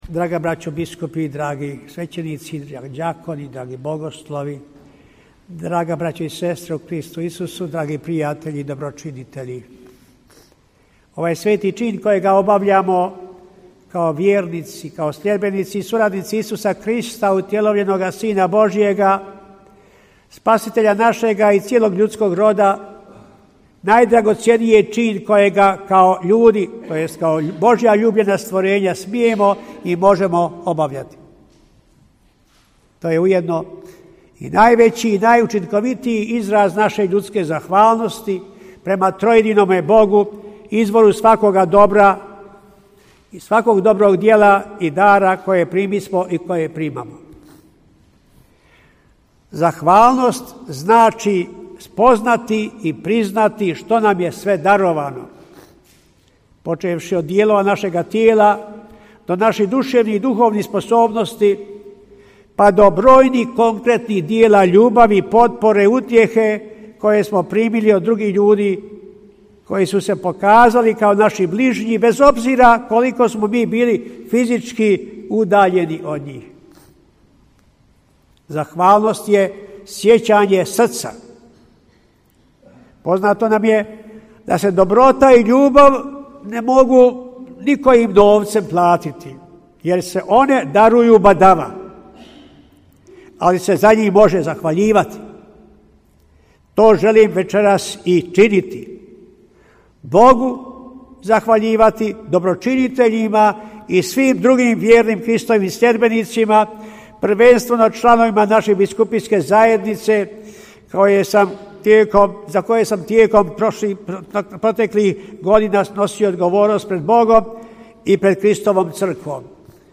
Audio: Propovijed biskupa Komarice na zahvalnom Euharistijskom slavlju u banjolučkoj katedrali
U večernjim satima u petak, 1. ožujka 2024. u banjolučkoj katedrali sv. Bonaventure dosadašnji biskup Franjo Komarica predvodio je zahvalno Euharistijsko slavlje na kraju obavljanja službe banjolučkog biskupa.